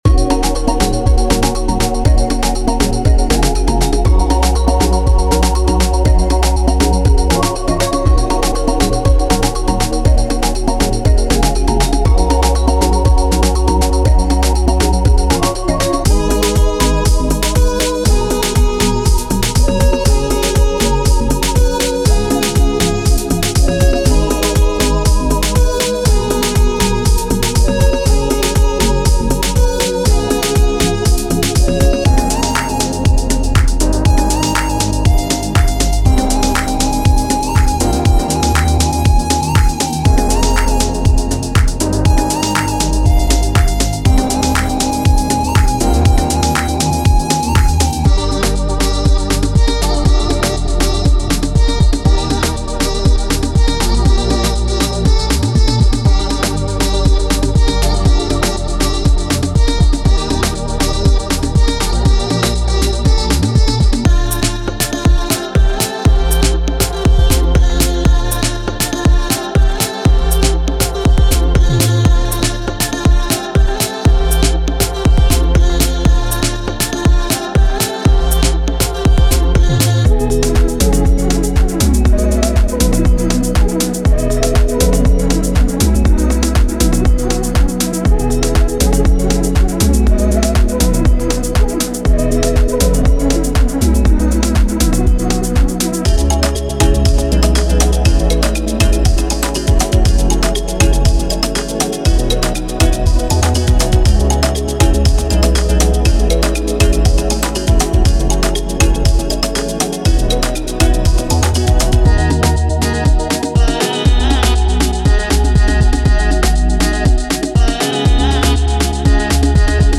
15 Bass Loops: Deep, groovy basslines that provide a solid foundation, blending traditional Afrobeat grooves with modern electronic influences.
30 Drum Loops: A versatile selection of drum loops featuring rich percussive elements and intricate rhythms that capture the essence of Afrobeat while pushing boundaries.
30 Synth Loops: Futuristic synth loops that deliver unique melodies and textures, merging the organic feel of African music with cutting-edge electronic sounds.
13 Vox and FX Loops: A mix of vocal chops, effects, and atmospheres to add character, depth, and an authentic Afro-inspired vibe to your productions.